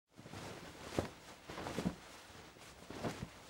cloth_sail1.L.wav